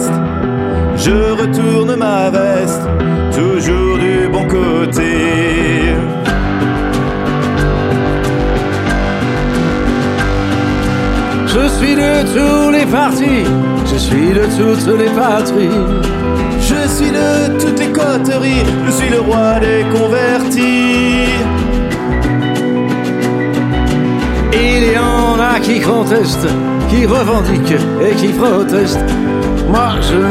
0 => "Chanson francophone"